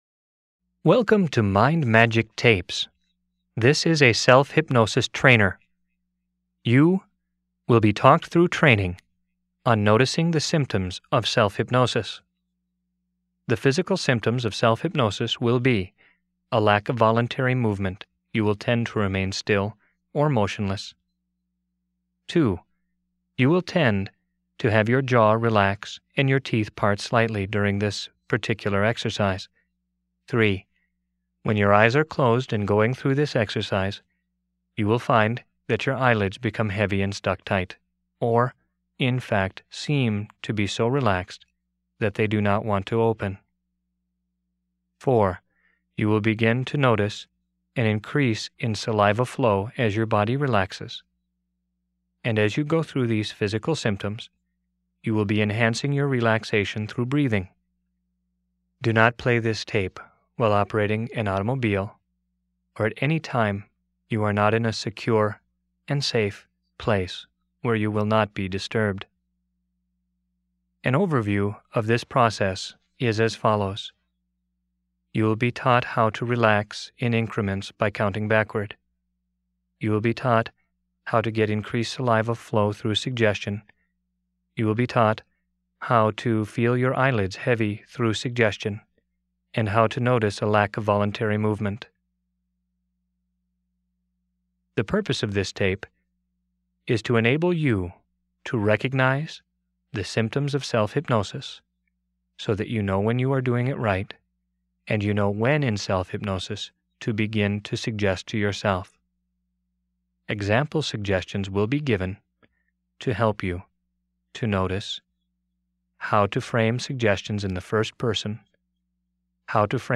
Free Hypnosis Download
Self_Hypnosis.mp3